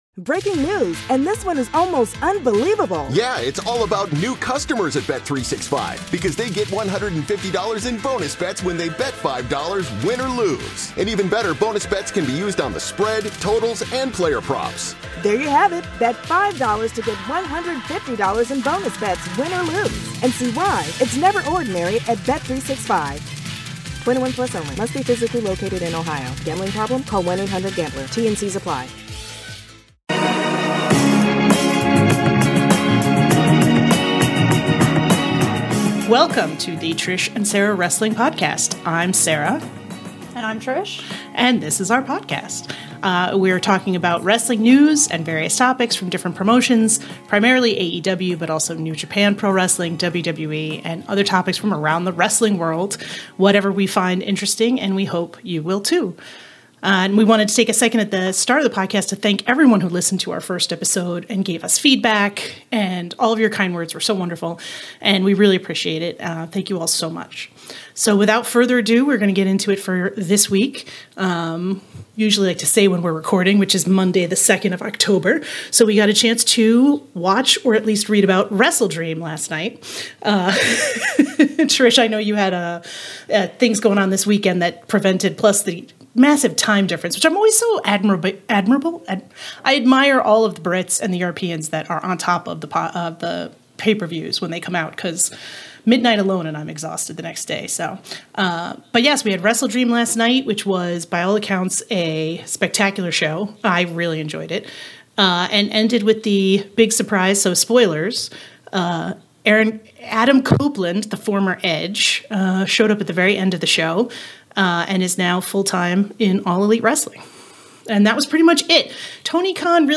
are back in the studio to discuss WrestleDream—the tickets, the PPV buys, the matches and the arrival of Adam Copeland! Plus all the latest WWE merger news and how the new Smackdown rights deal may affect both major companies.